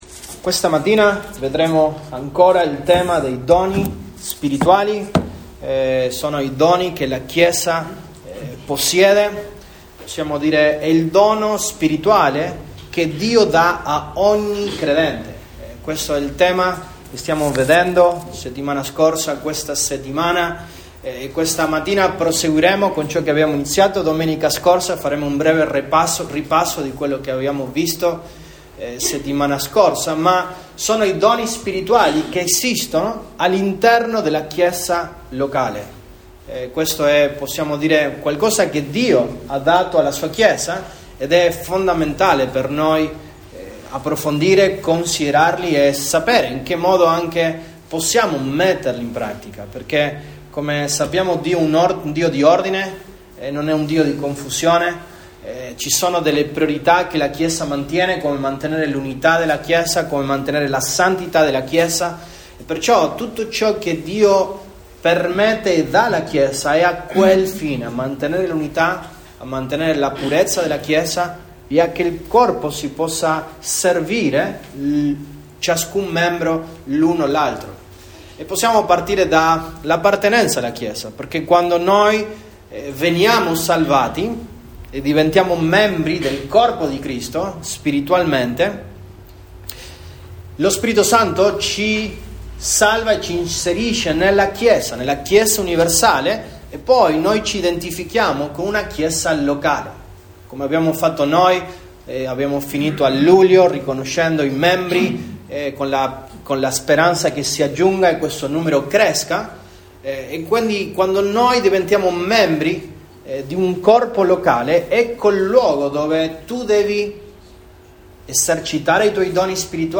Sermoni